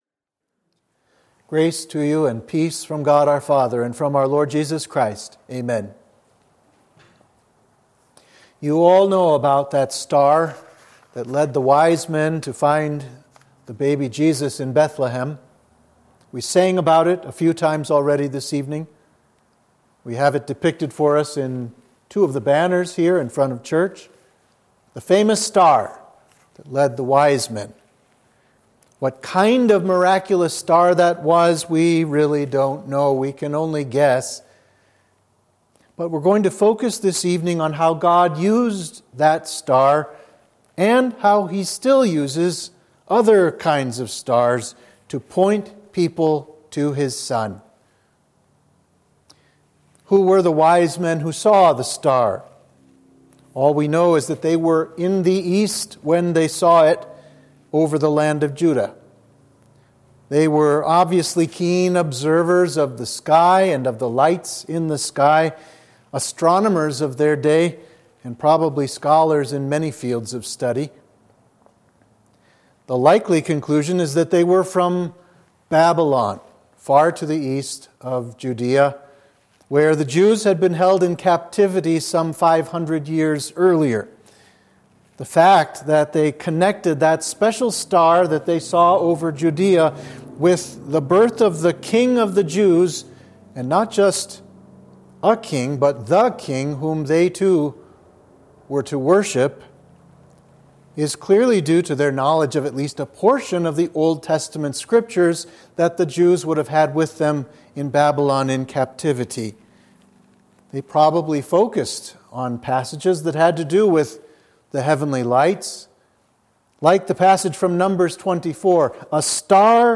Sermon for the Epiphany of Our Lord